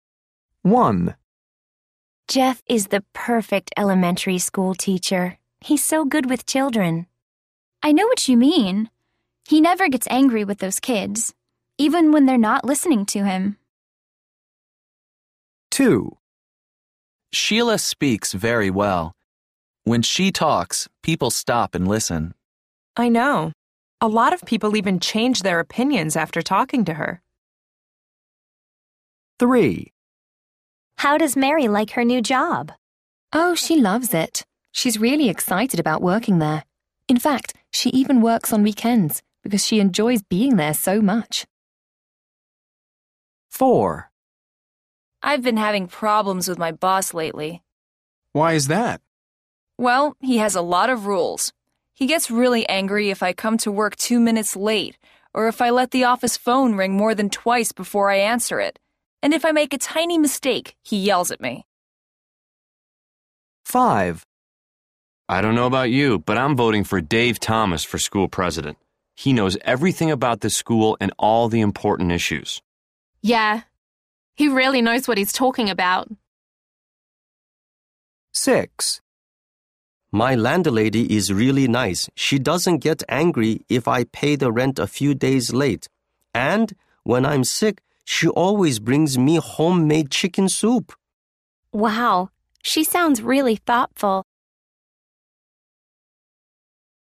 Some friends are talking about people they know.